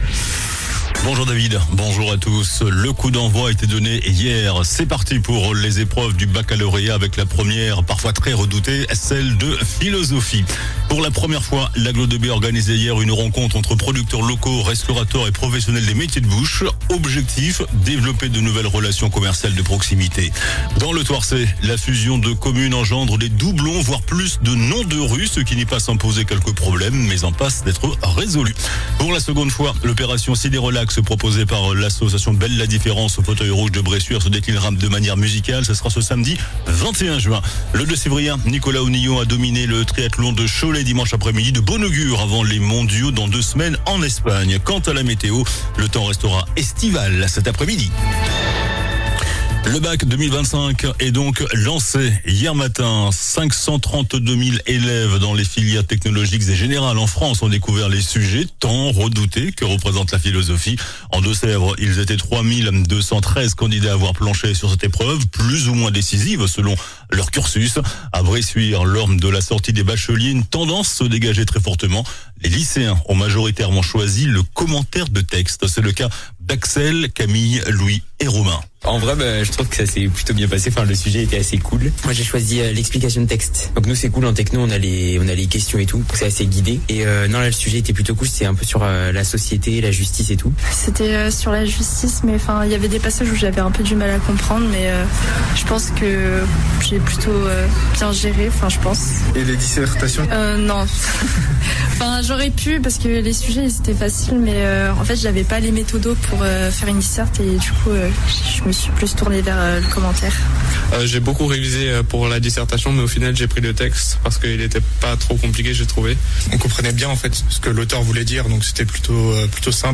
JOURNAL DU MARDI 17 JUIN ( MIDI )